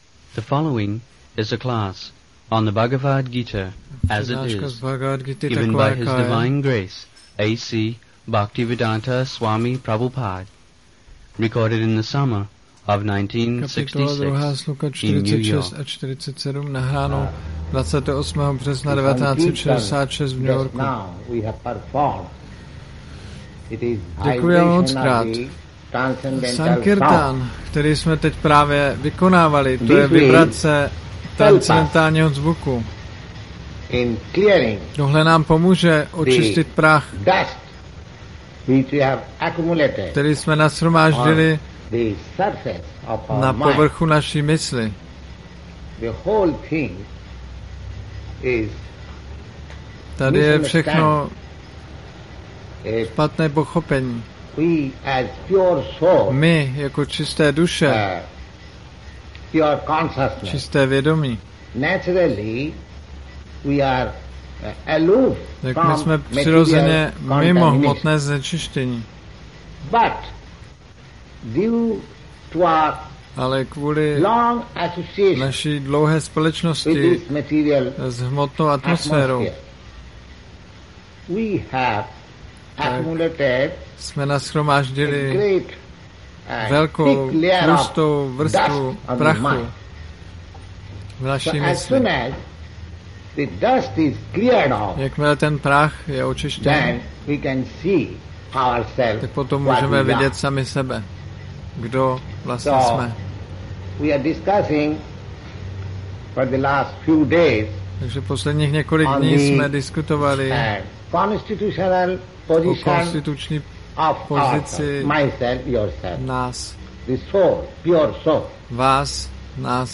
1966-03-28-ACPP Šríla Prabhupáda – Přednáška BG-2.46-47 New York